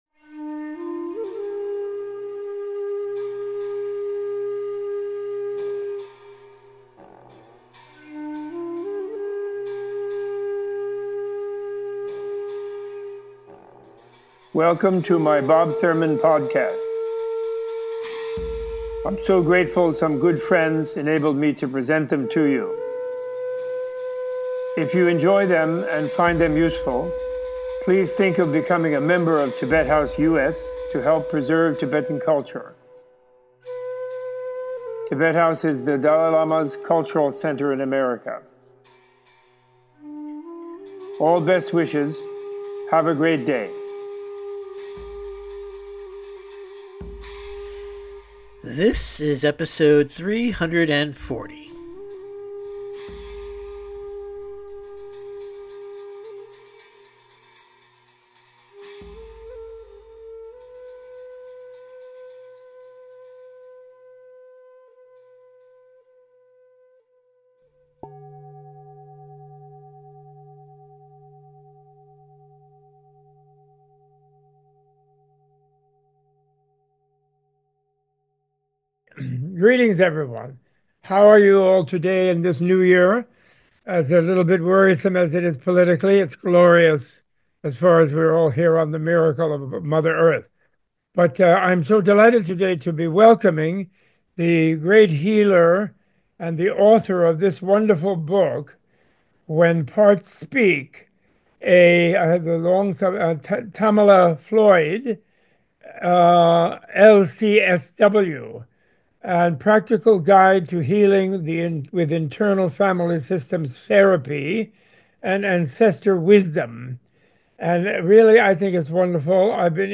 A Tibet House US Menla Conversation